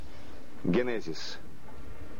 Die englischen Episodentitel werden, wie in den anderen Staffeln auch, von einer männlichen Stimme ins russische übersetzt.